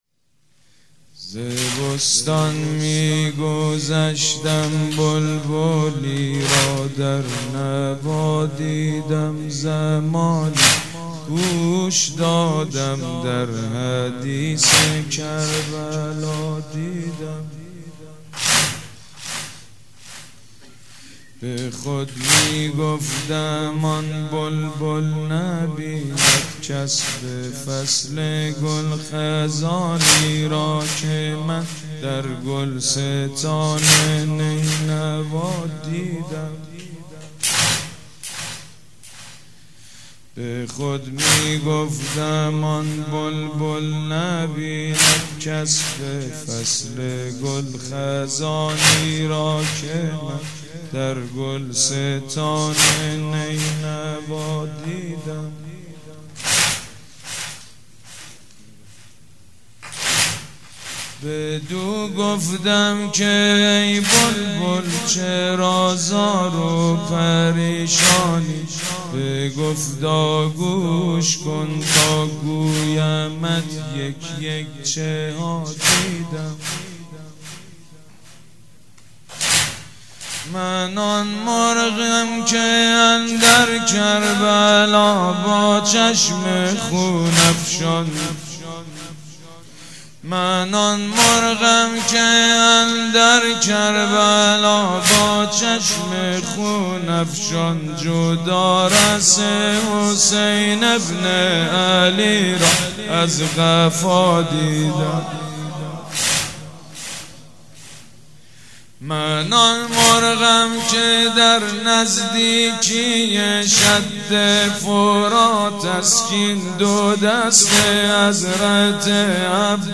مداح
مراسم عزاداری شب اول